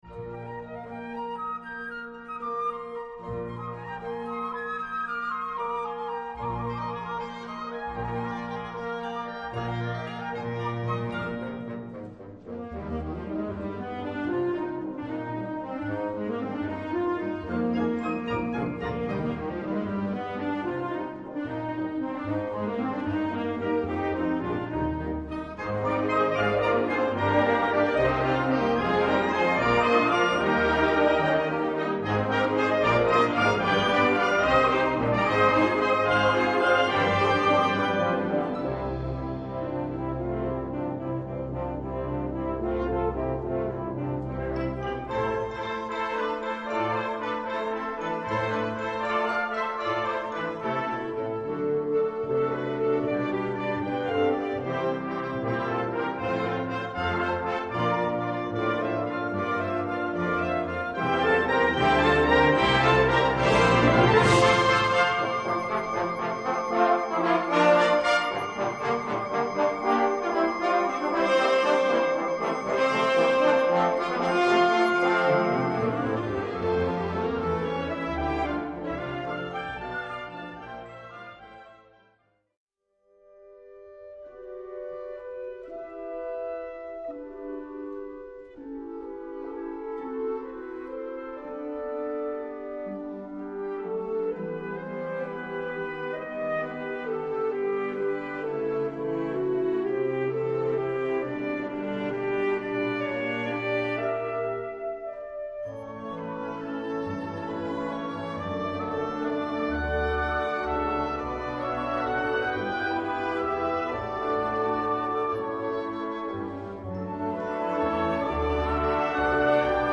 Gattung: Suite
Besetzung: Blasorchester